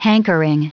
Prononciation du mot hankering en anglais (fichier audio)
Prononciation du mot : hankering
hankering.wav